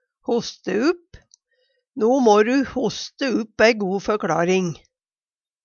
hoste upp - Numedalsmål (en-US)